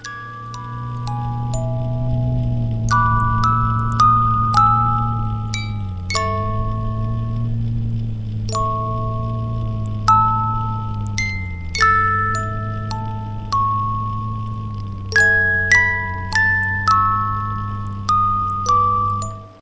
Melody Phone (